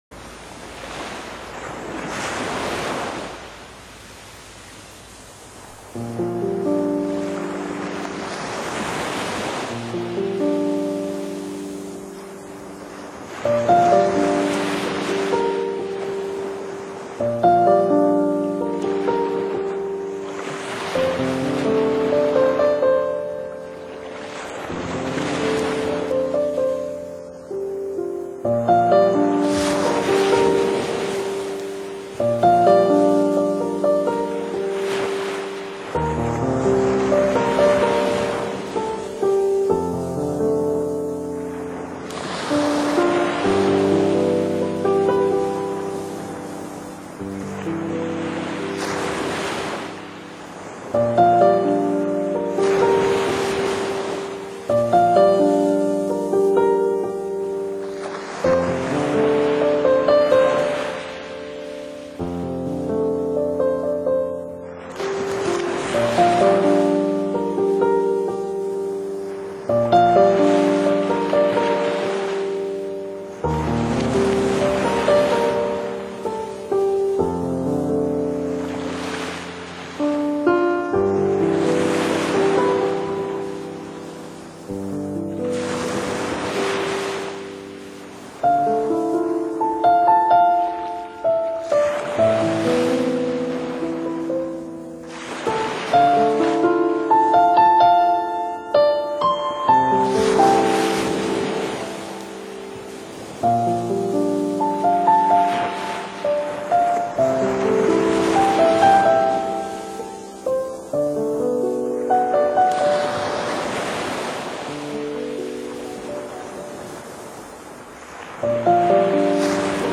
台湾沿海实地录音，临场海浪声绝无仅有
钢琴
海声录音
鸟声录音